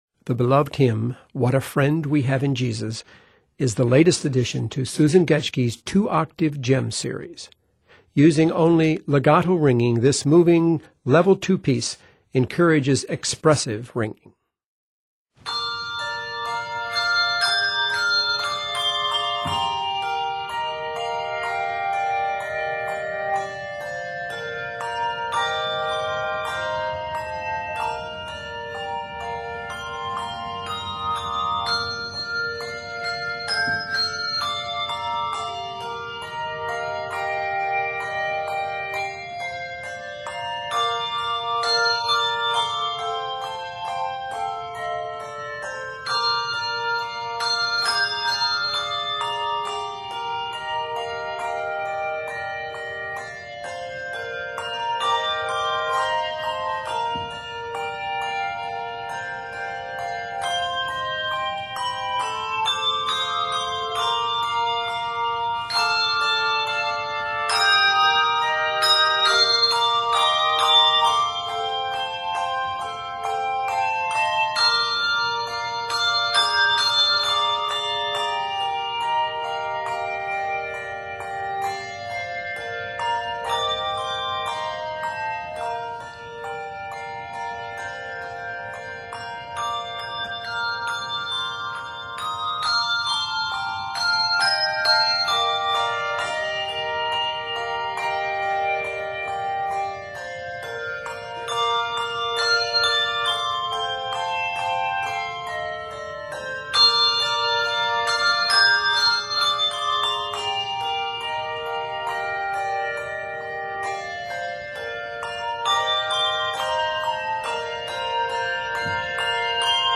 Pleasantly set in Bb Major, the arrangement is 44 measures.